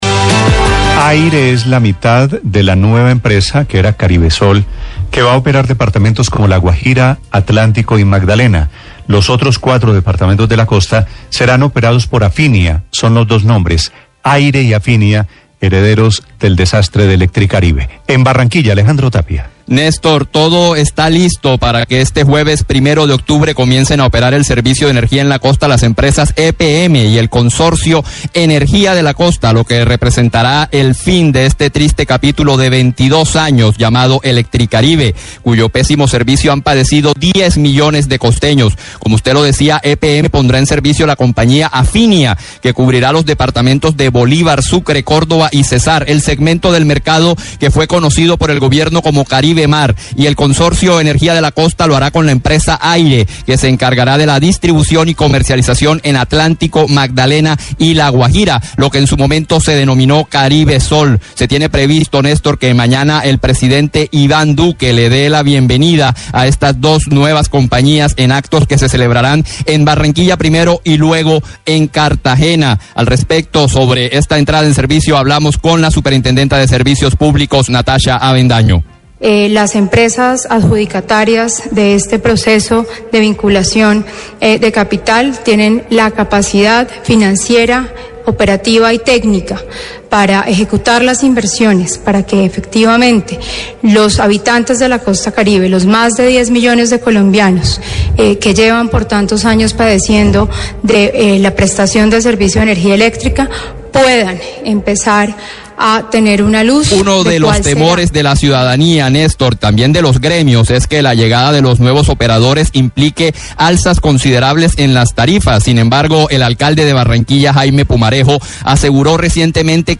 Radio
Entrevista a Ministro de Minas y Energía, Diego Meza.